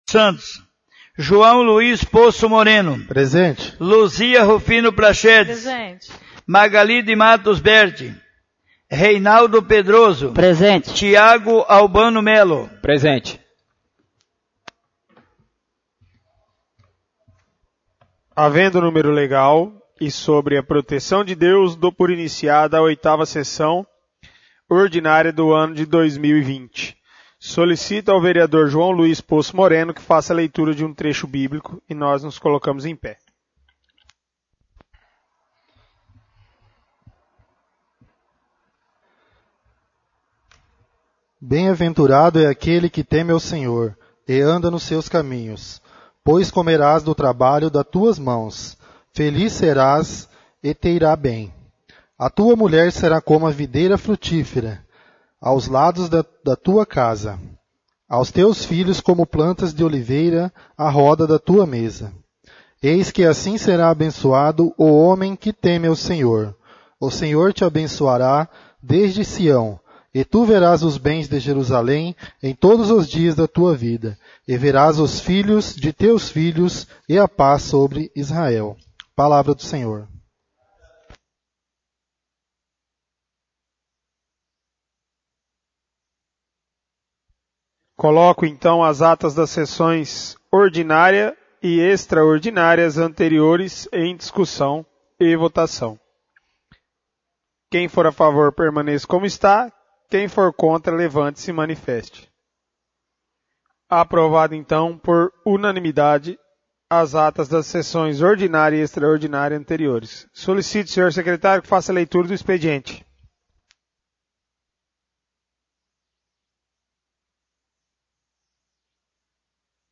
8° Sessão Ordinária — CÂMARA MUNICIPAL